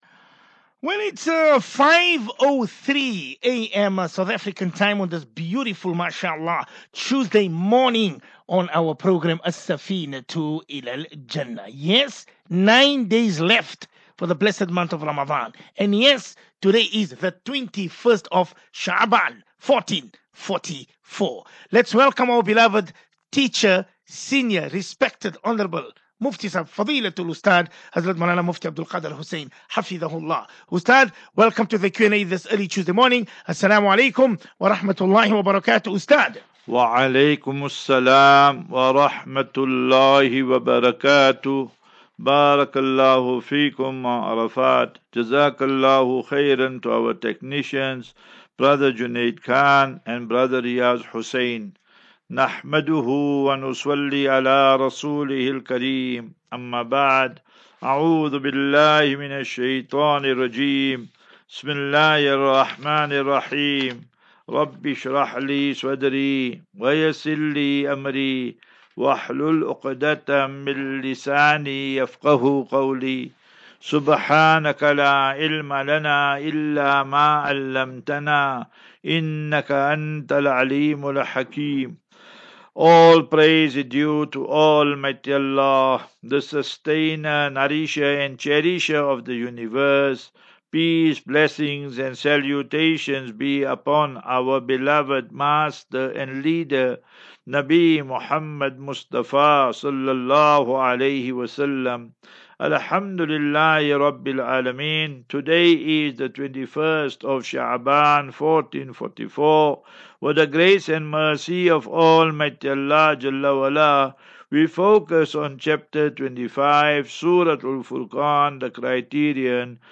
As Safinatu Ilal Jannah Naseeha and Q and A 14 Mar 14 Mar 23 Assafinatu